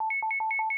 ALERT_1.WAV